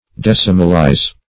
Decimalize \Dec"i*mal*ize\, v. t.